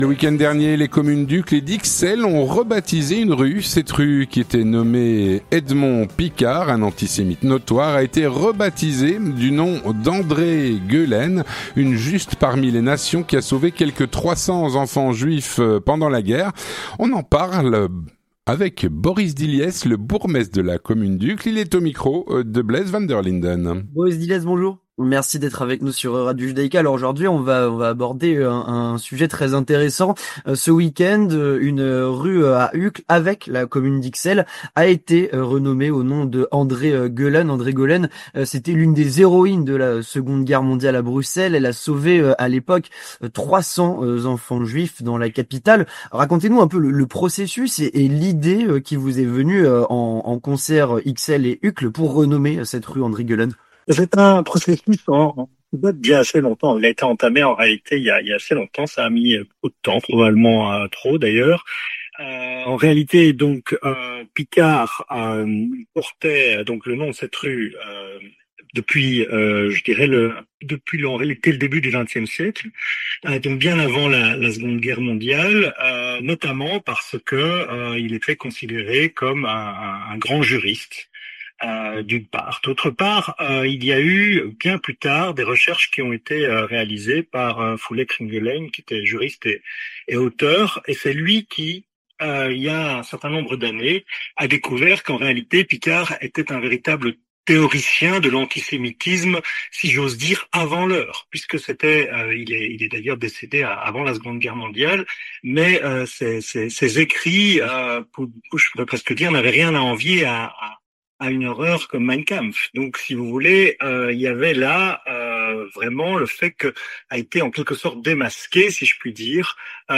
On en parle avec Boris Dilliès, bourgmestre d’Uccle.